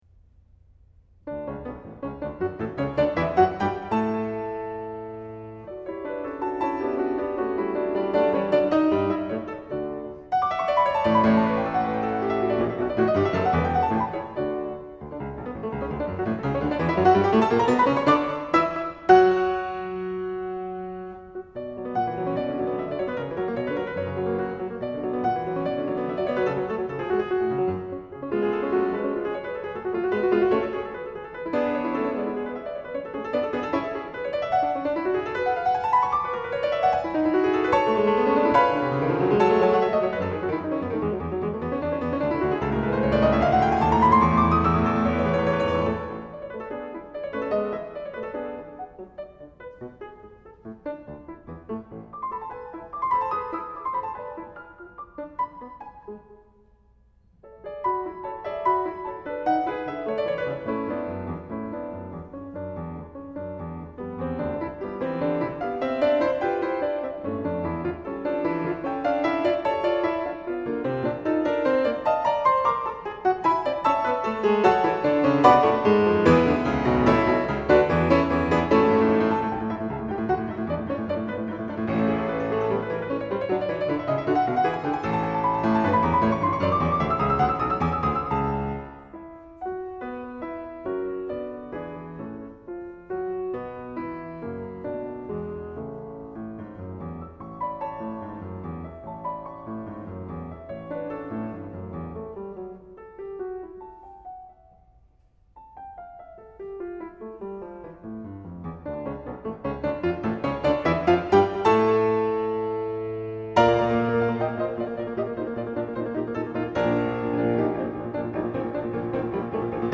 Recitals - July 17, 2008